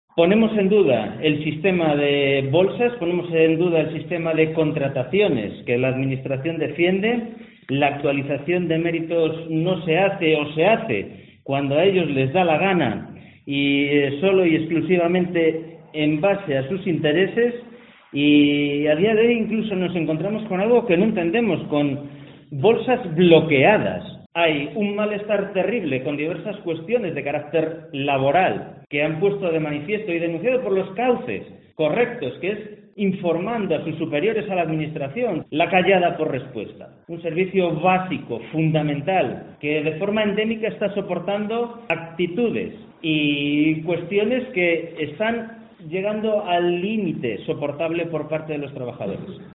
AUDIO: Escucha aquí al procurador socialista Javier Campos